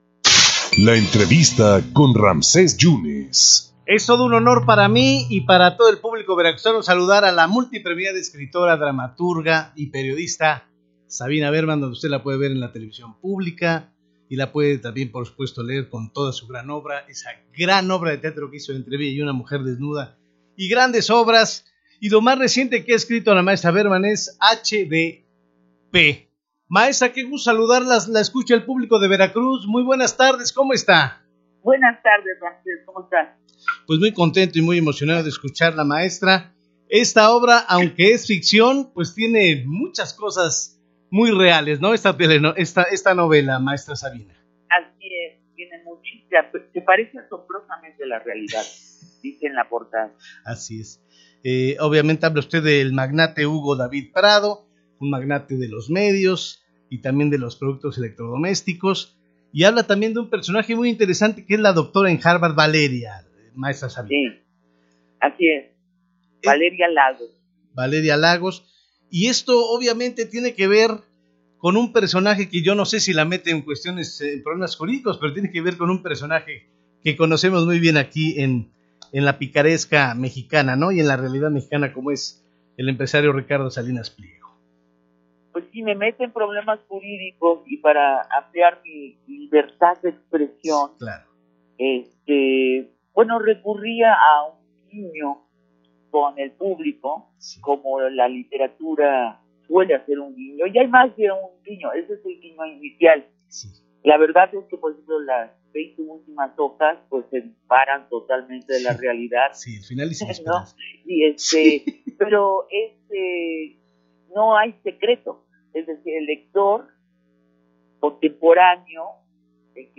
Redacción/Xalapa.- La escritora y periodista Sabina Berman platicó para En Contacto sobre su última obra ficticia donde hace guiños a la realidad mexicana y sus circunstancias históricas.